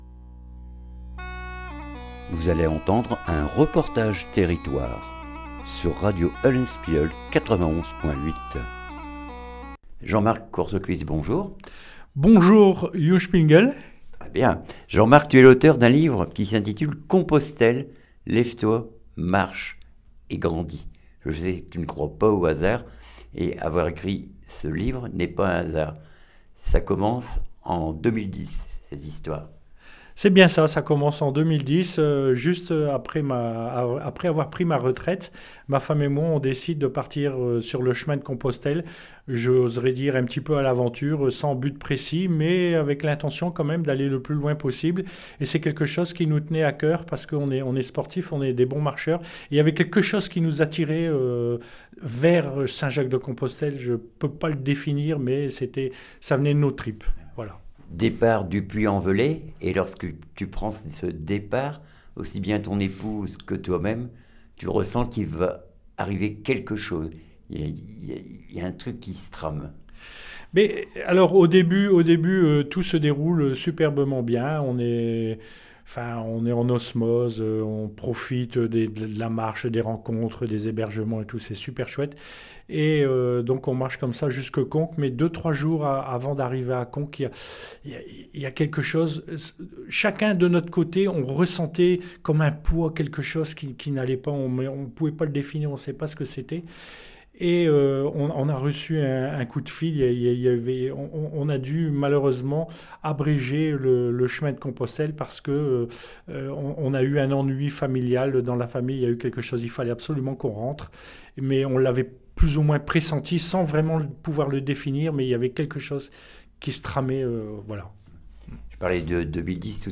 REPORTAGE TERRITOIRE COMPOSTELLE LEVE TOI MARCHE ET GRANDIS